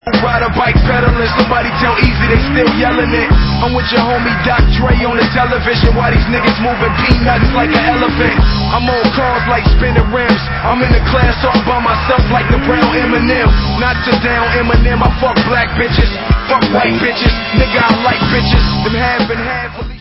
Dance/Hip Hop